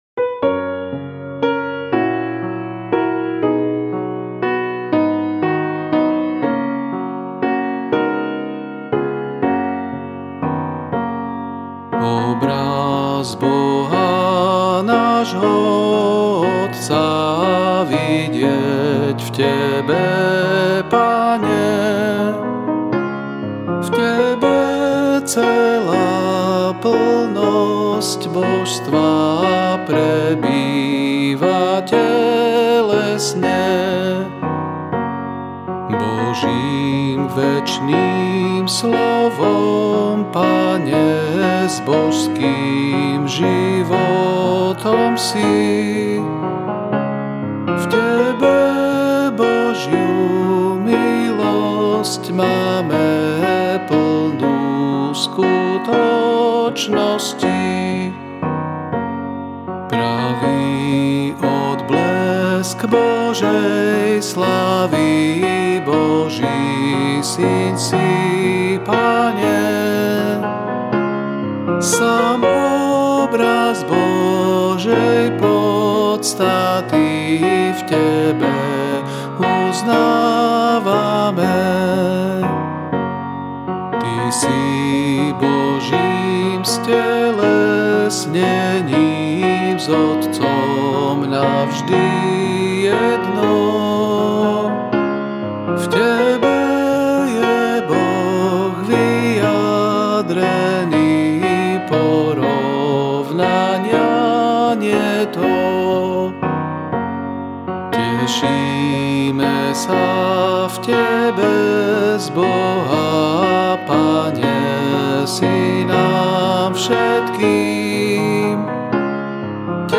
Ab Major